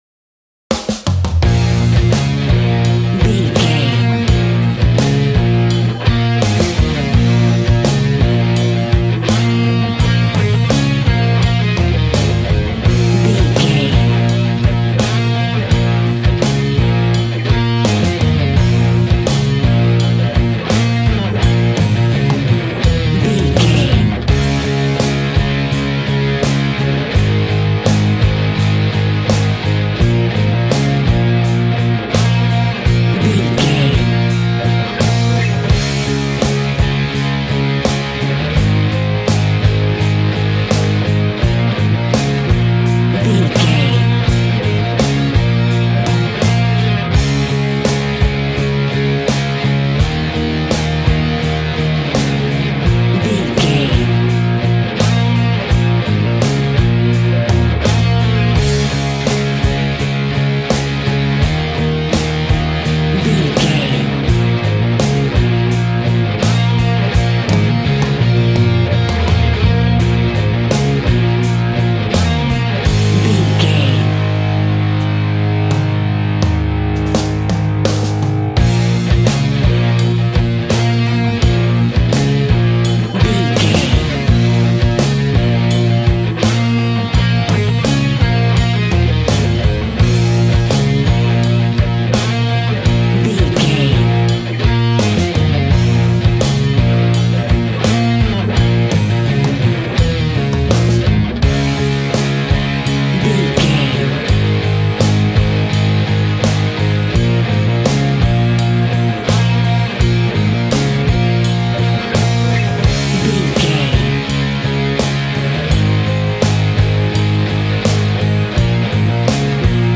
Aeolian/Minor
B♭
groovy
powerful
electric guitar
bass guitar
drums
organ